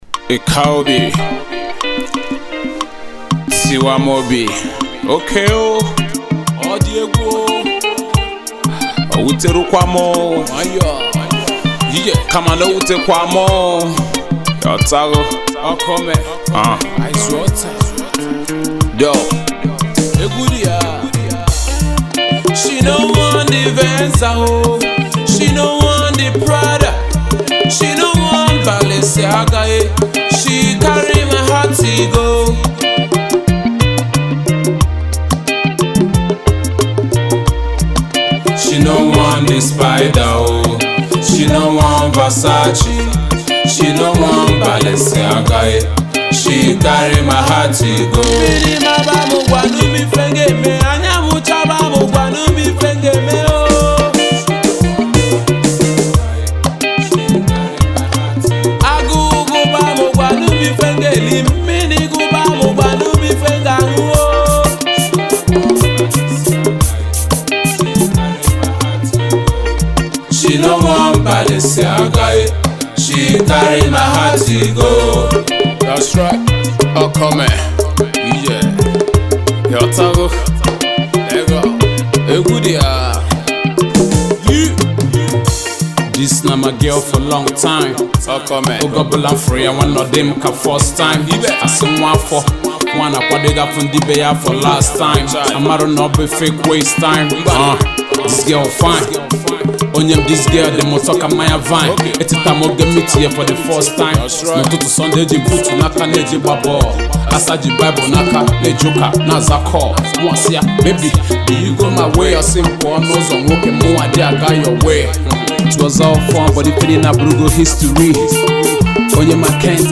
Home Afro-pop Audio
classic high life record